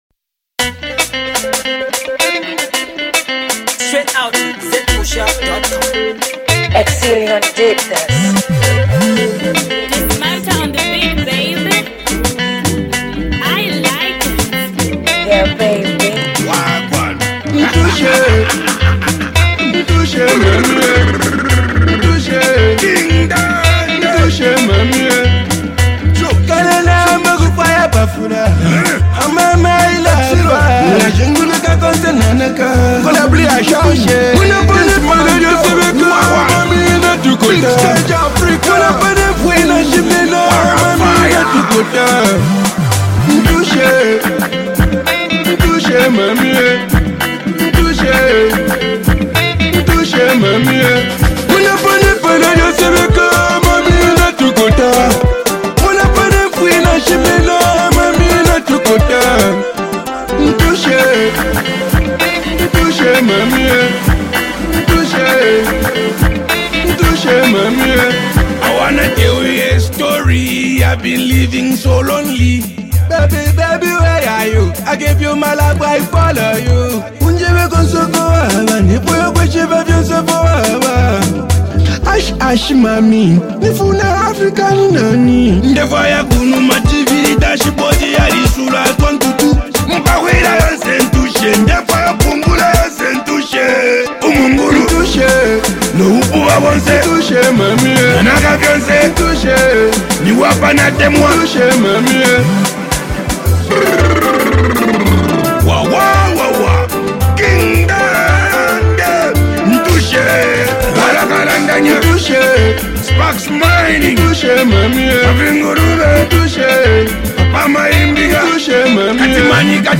An RnB joint